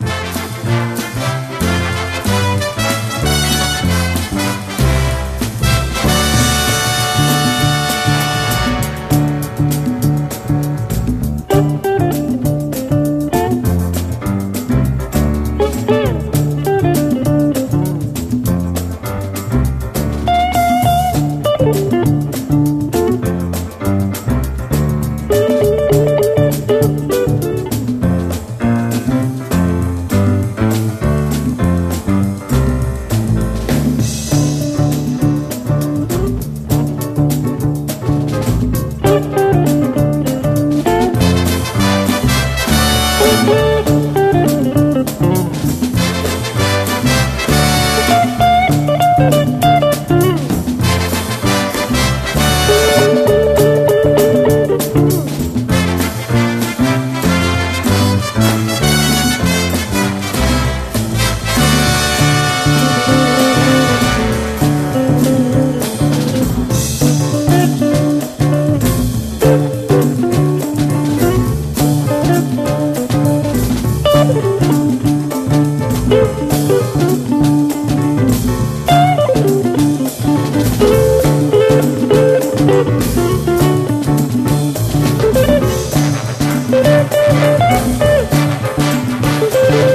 JAZZ / OTHER / BLUES